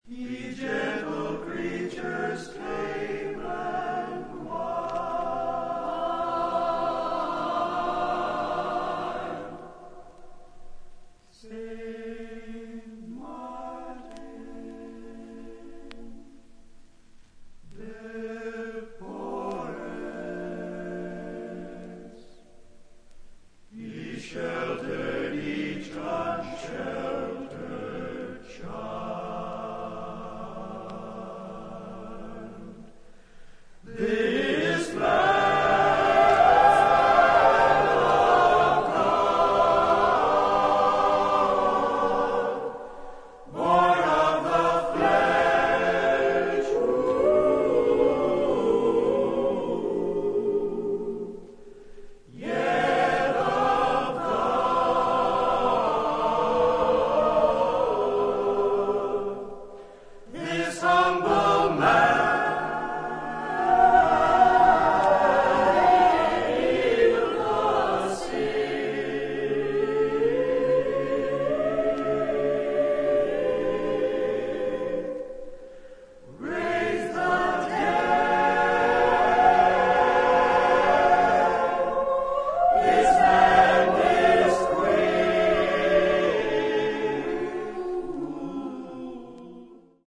ジャズやブルースをベースにしつつも、宗教音楽やアヴァンギャルドな要素が含まれる唯一無二のアルバム。